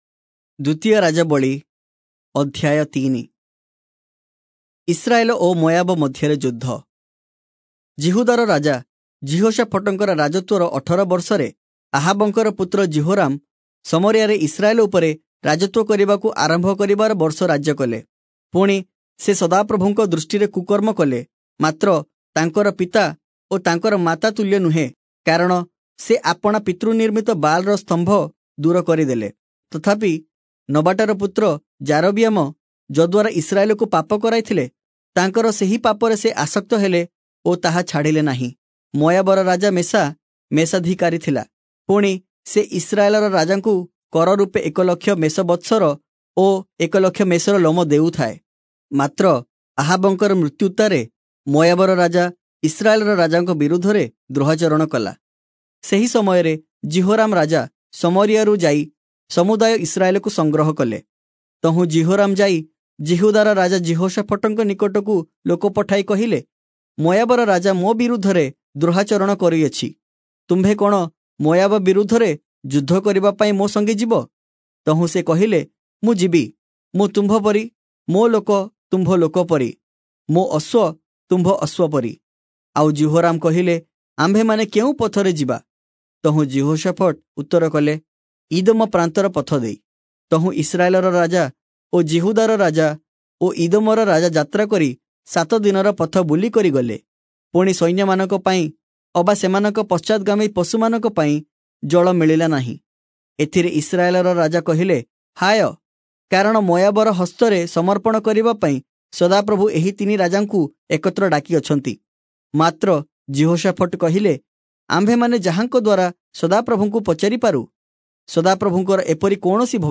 Oriya Audio Bible - 2-Kings 5 in Irvor bible version